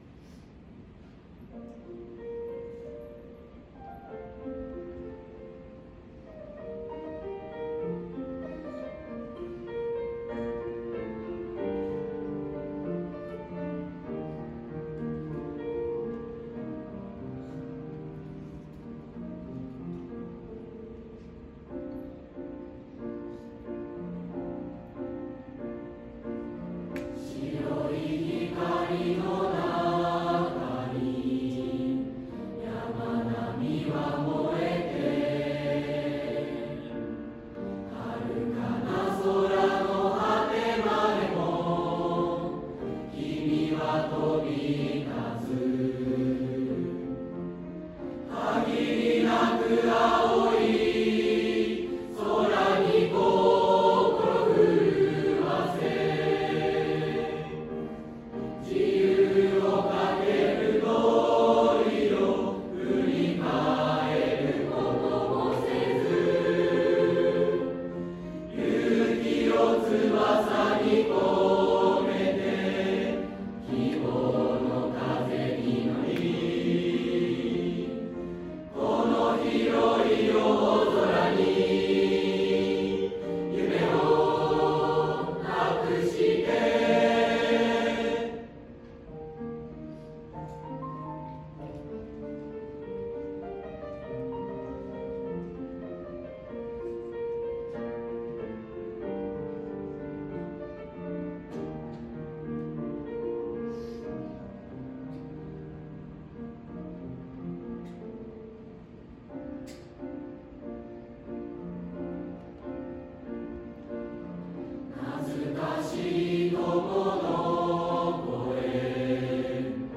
最後に３年生からメッセージと卒業式で歌う「旅立ちの日に」を１・２年生に向けて披露してくれました。３年生２１９名の歌声が体育館に響き、卒業生を送る会にふさわしい締めくくりとなりました。 ３年生旅立ちの日に☜３年生の歌声です。